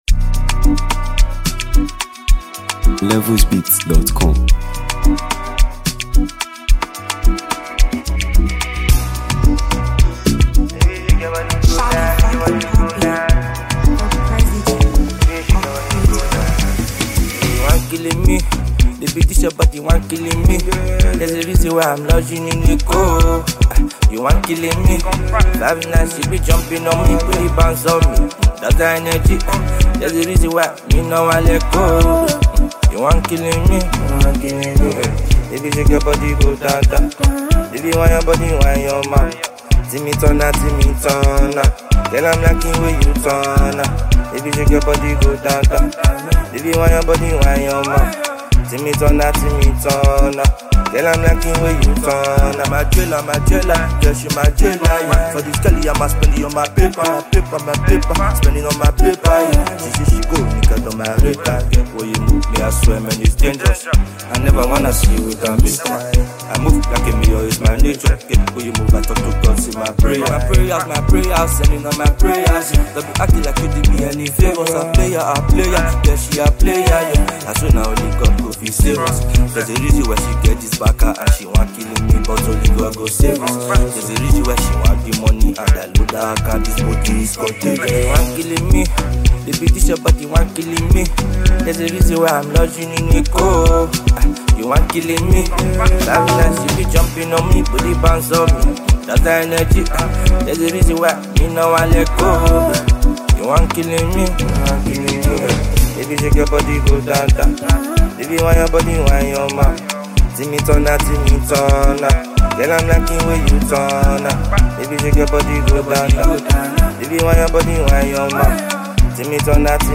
vibrant, addictive